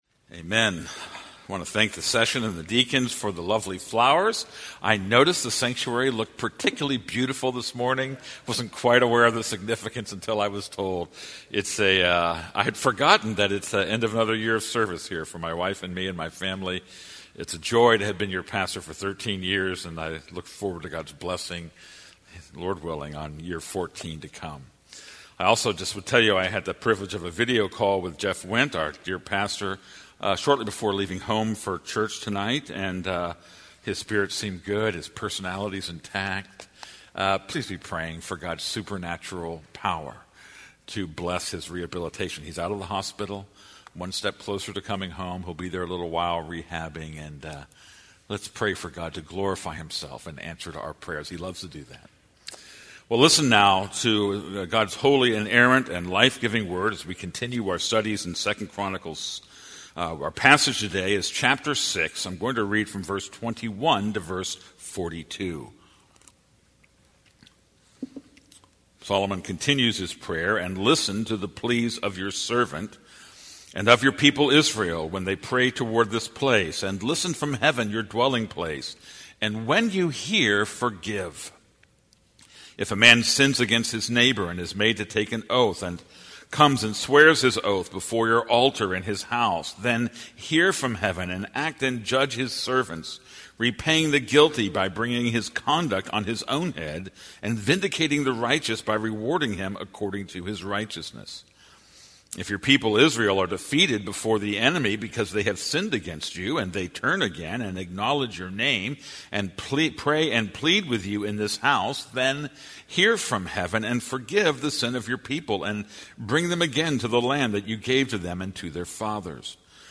This is a sermon on 2 Chronicles 6:21-42.